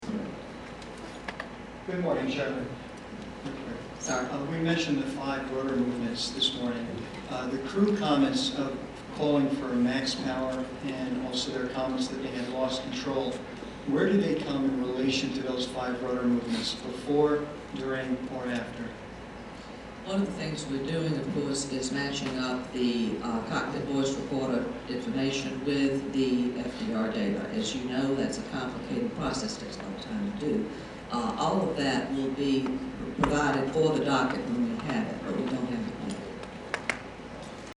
At the May 3rd NTSB/NASA Press Conference in Hampton, Virginia, I had the opportunity to ask NTSB Chairman Marion Blakey the following question: "We mentioned the five rudder movements this morning. The crew comments calling for max power and also their comments that they had lost control, where do they come in relation to those five rudder movements ... before, during, or after?"